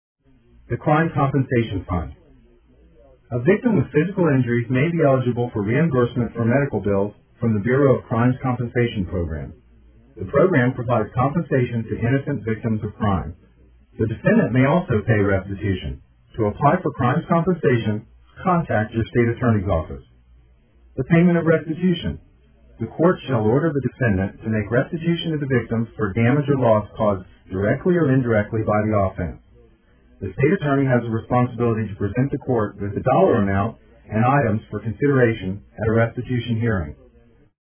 DUI Progression Described By a Board Certified Lawyer Go Over Each Step of a DUI in Tampa Courts